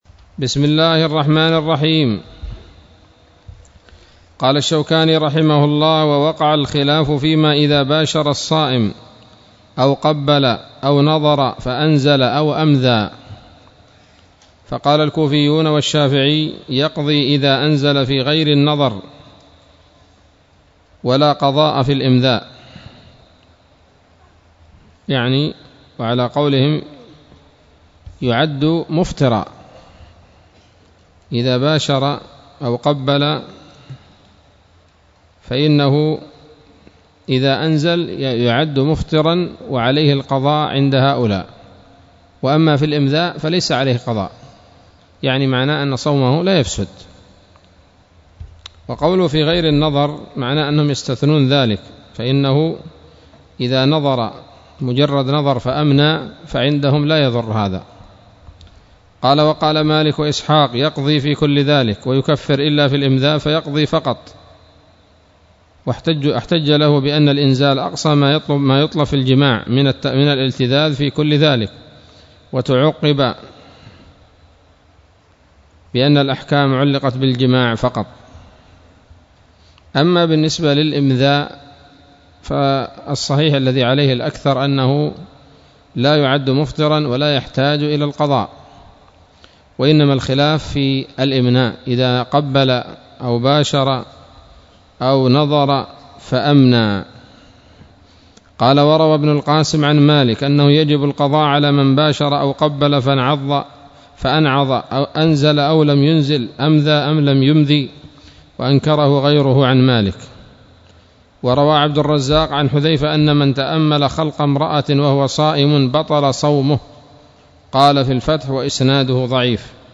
الدرس الخامس عشر من كتاب الصيام من نيل الأوطار